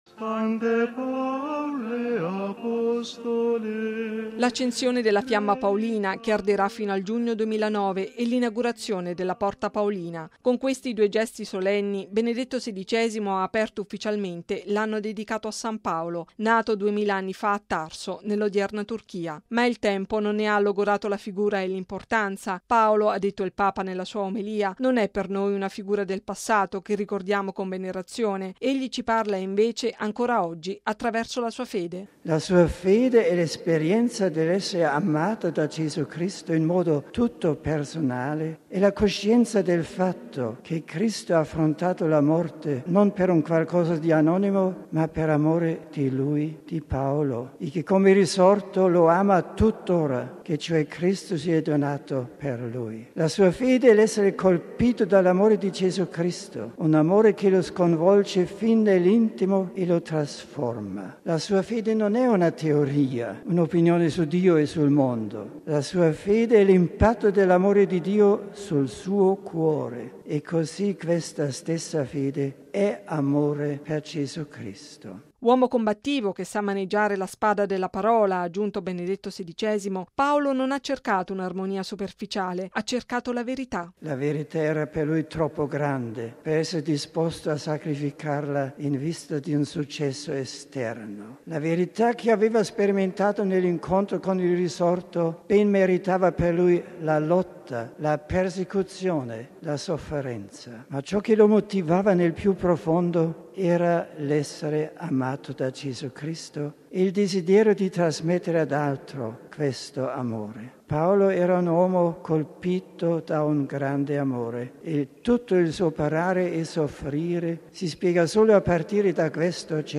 Solenne apertura ieri dell'Anno Paolino, nella basilica di San Paolo fuori le mura, presieduta dal Papa alla presenza del Patriarca di Costantinopoli
(canto)